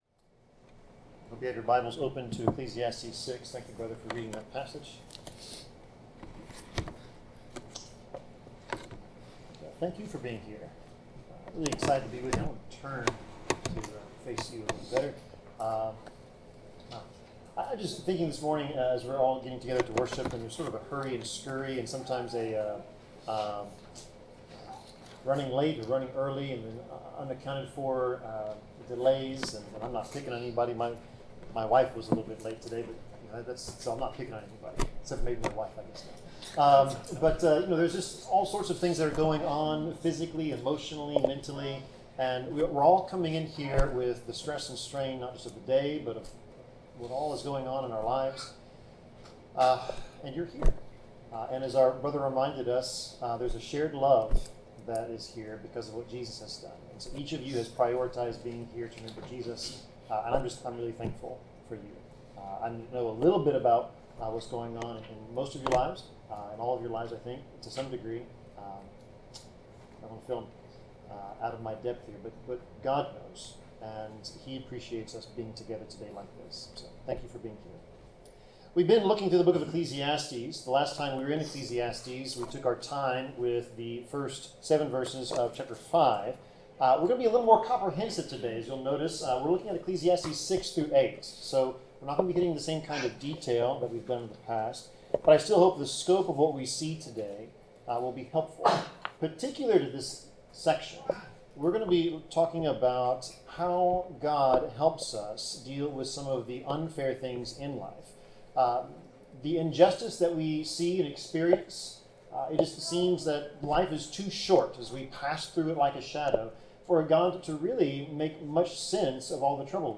Passage: Ecclesiastes 6-8 Service Type: Sermon